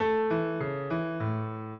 piano
minuet7-5.wav